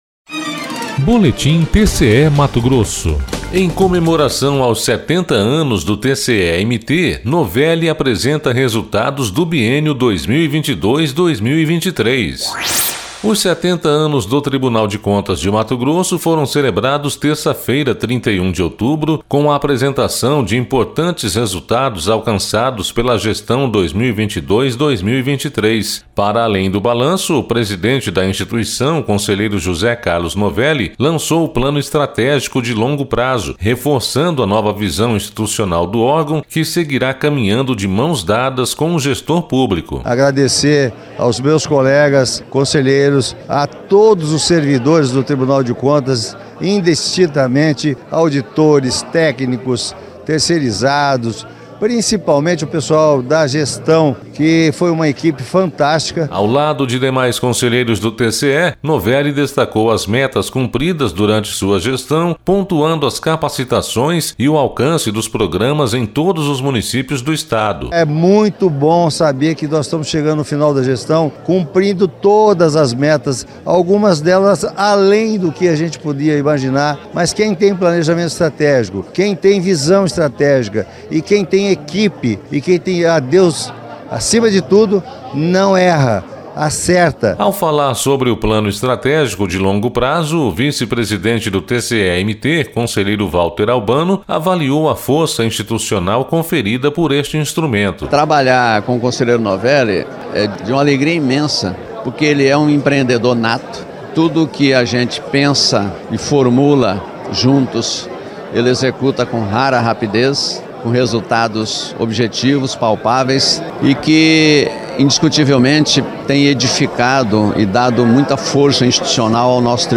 Sonora: José Carlos Novelli – conselheiro presidente do TCE-MT
Sonora: Valter Albano – conselheiro vice-presidente do TCE-MT
Sonora: Guilherme Antonio Maluf – conselheiro corregedor-geral do TCE-MT
Sonora: Alisson Carvalho de Alencar - procurador-geral do MPC-MT